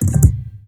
DRUMFILL09-R.wav